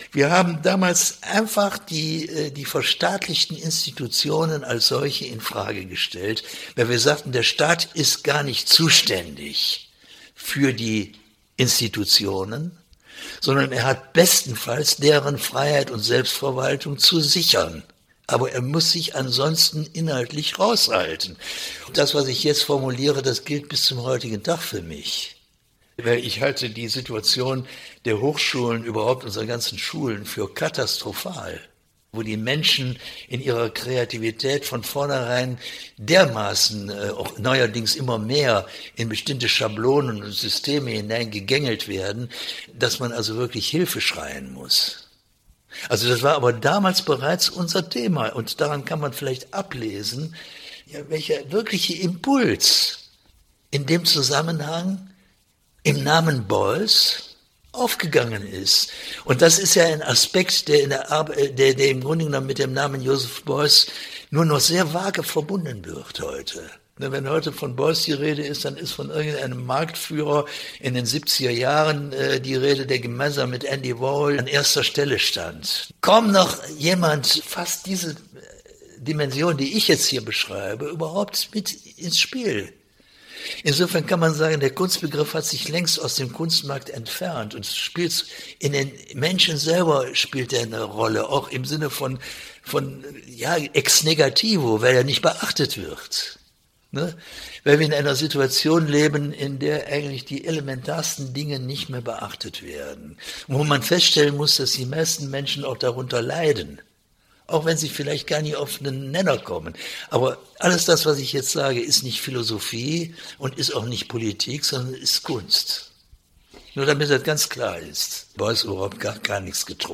Interview Audioarchiv Kunst